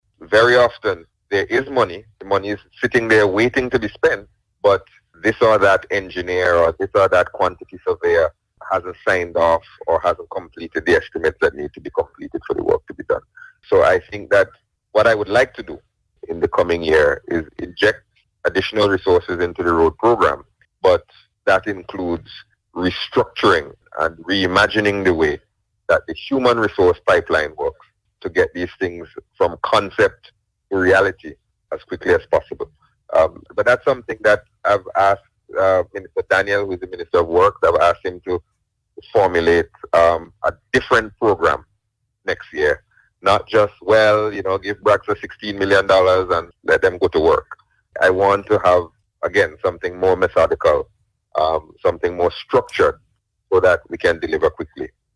The Minister addressed the issue while speaking on Radio on Sunday.